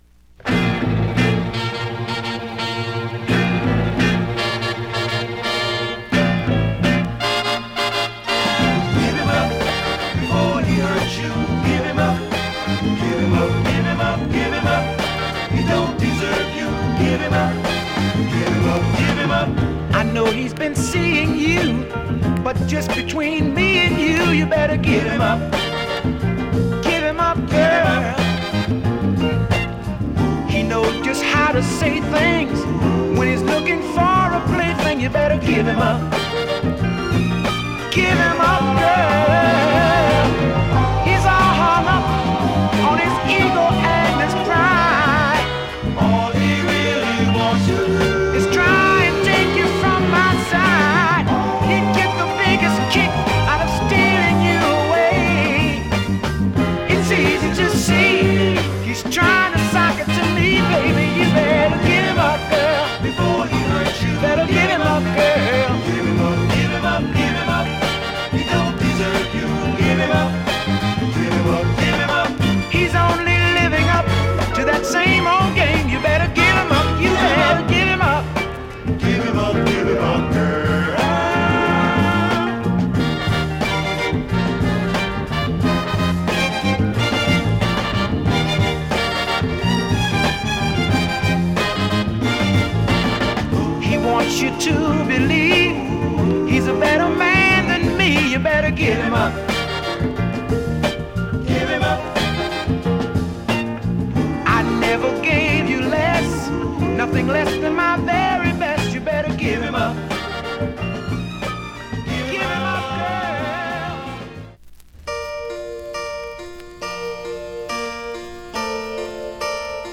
SOUL
SWEET SOUL CLASSIC !!